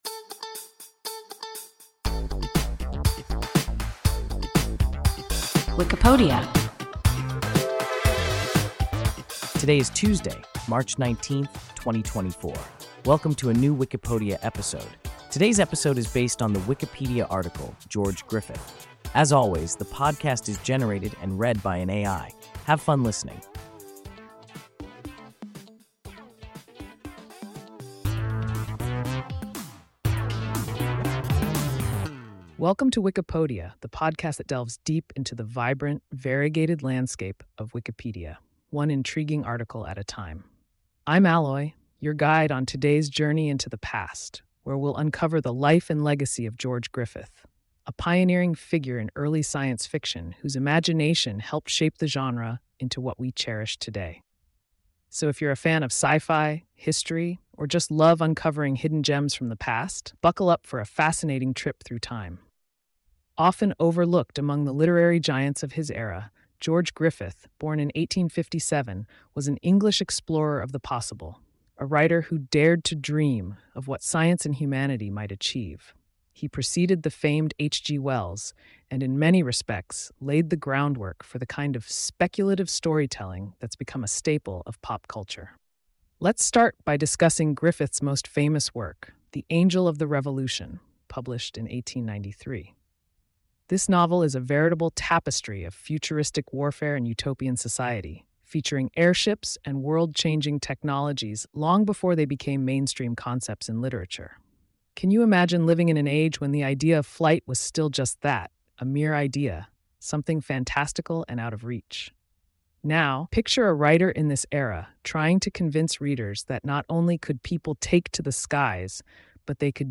George Griffith – WIKIPODIA – ein KI Podcast